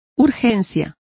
Complete with pronunciation of the translation of immediacies.